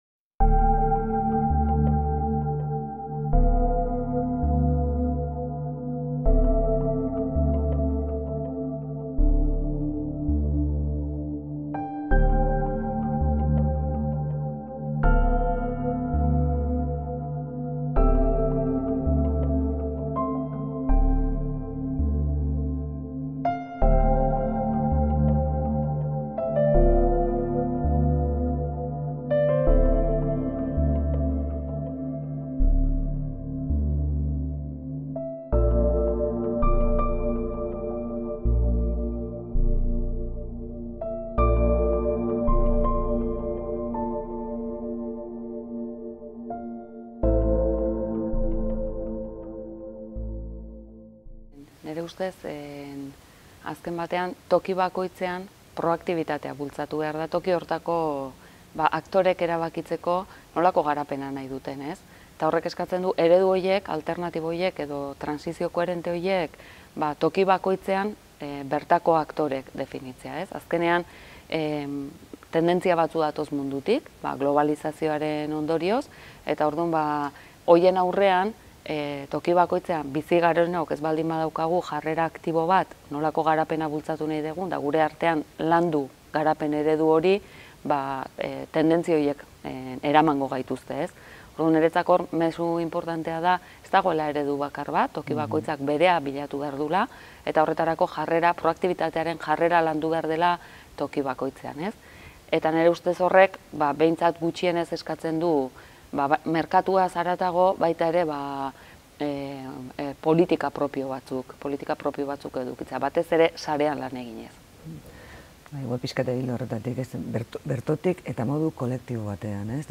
Bi ekonomialari bildu ditu Solasaldiak atalak